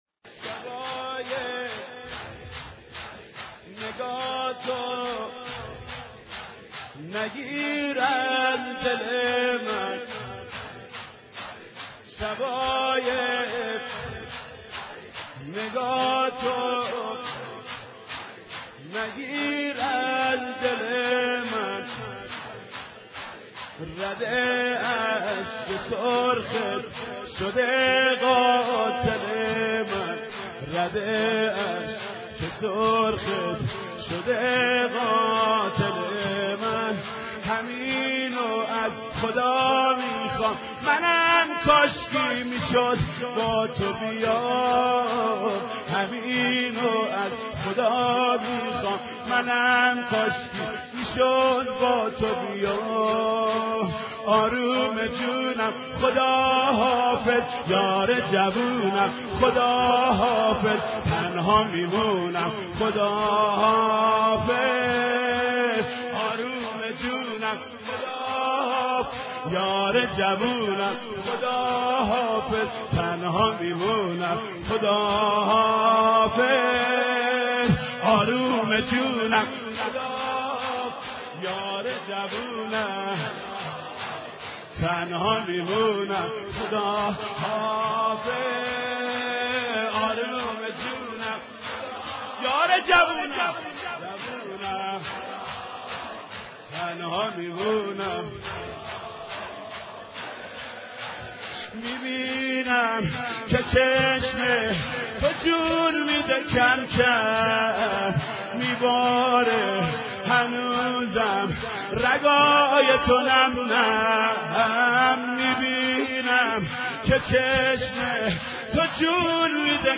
سینه زنی در عزای حضرت صدیقه(س) با صدای حاج محمود کریمی (5:59)